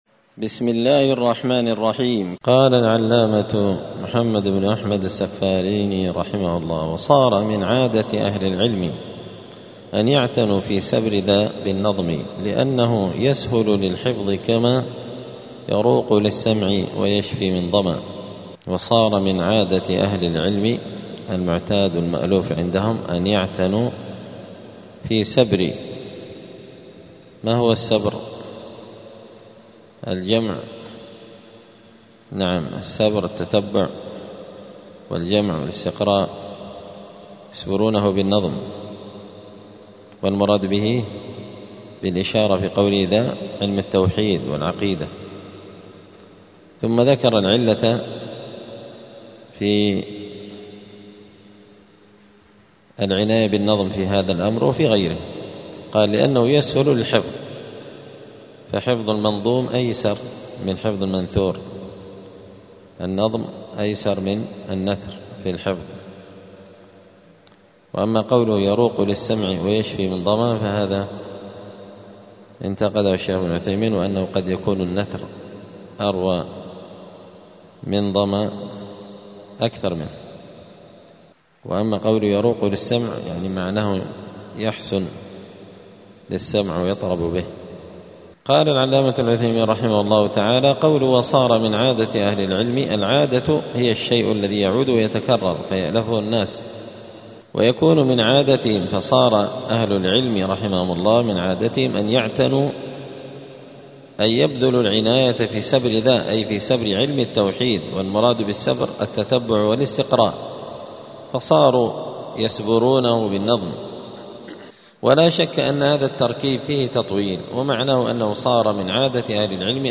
دار الحديث السلفية بمسجد الفرقان قشن المهرة اليمن
الدروس اليومية